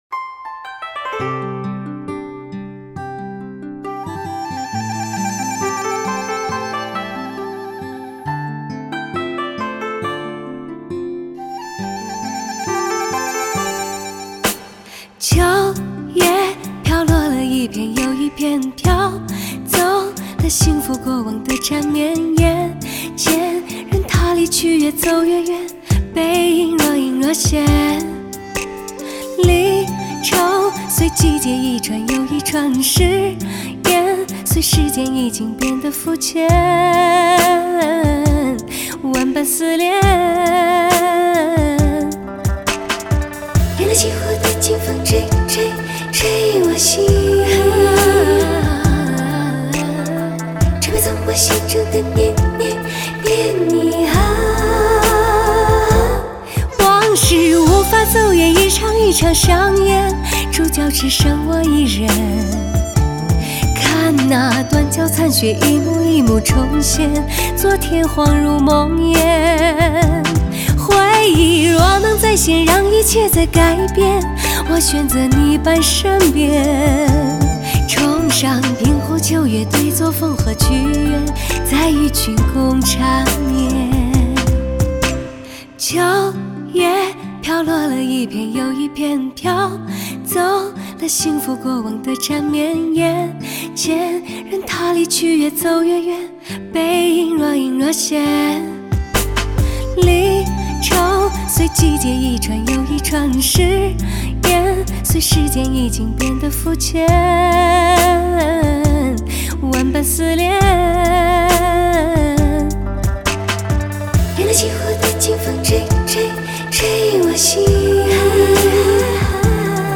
低吟浅唱有一股将人骨头变酥的魔力